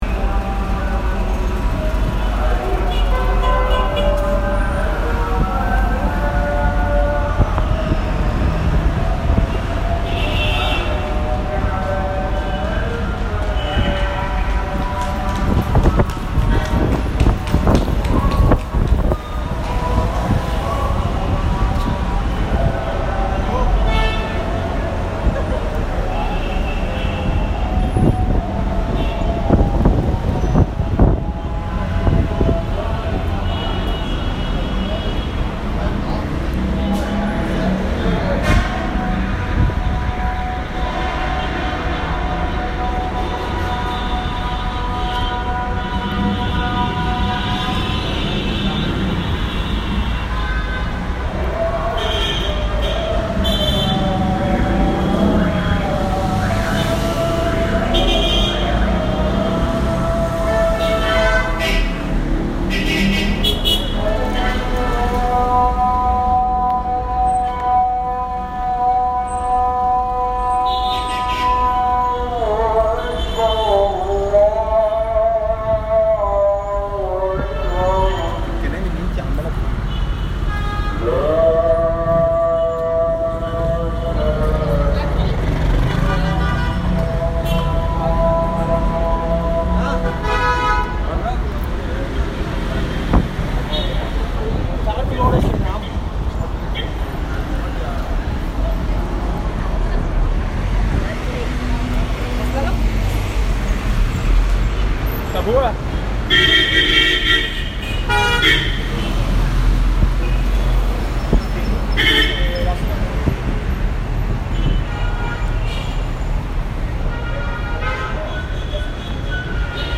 Cairo streets at night
Sunset in Cairo, by the Nile River, during evening call to prayers.